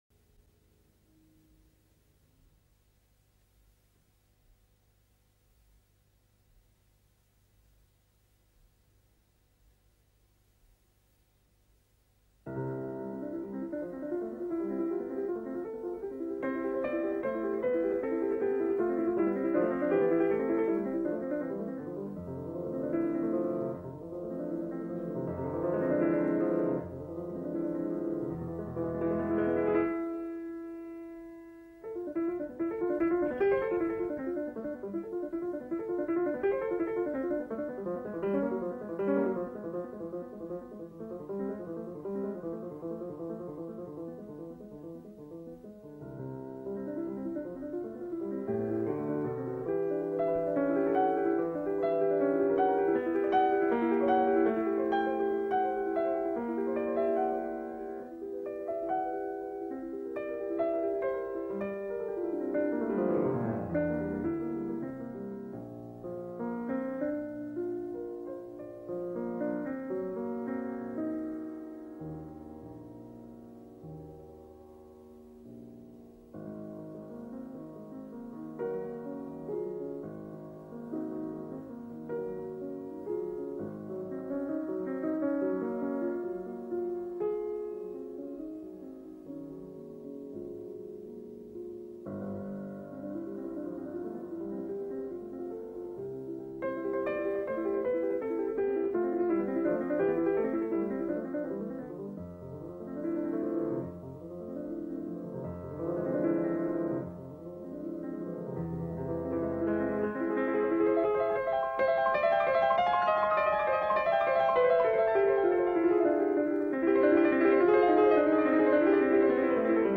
pianista